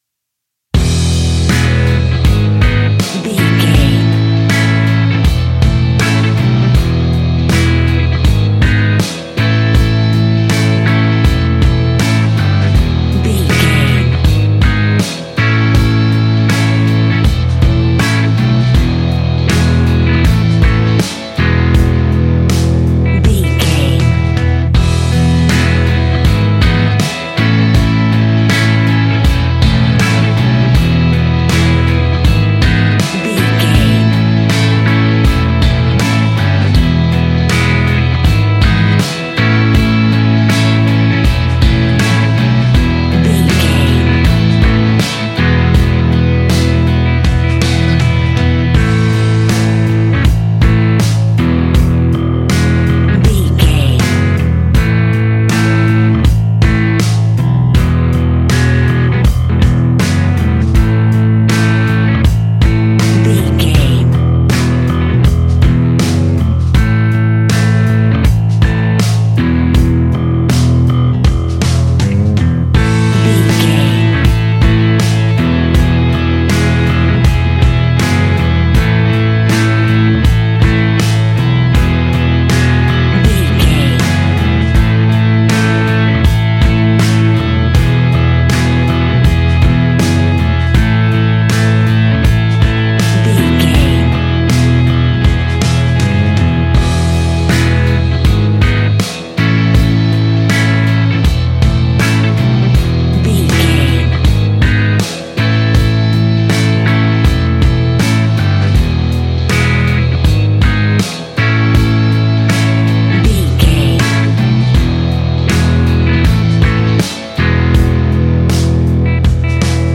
Ionian/Major
indie pop
energetic
uplifting
instrumentals
upbeat
groovy
guitars
bass
drums
piano
organ